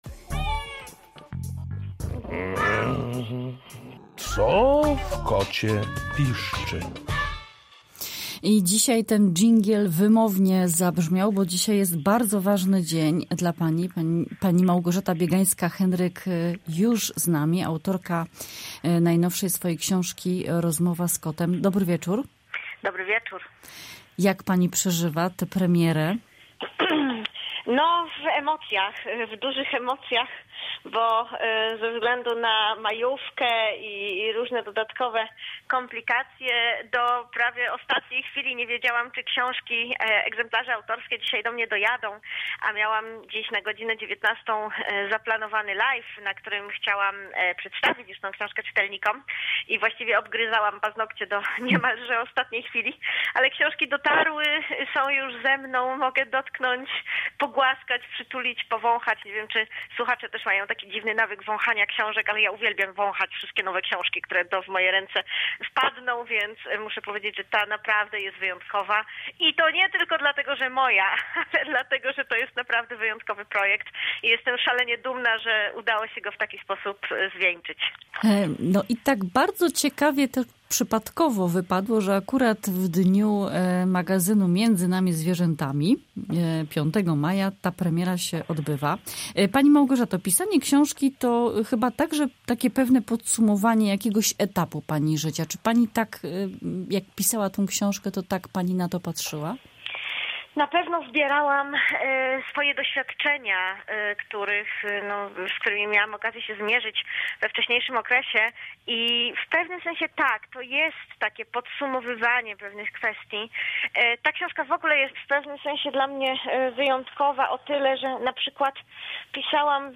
Rozmowa: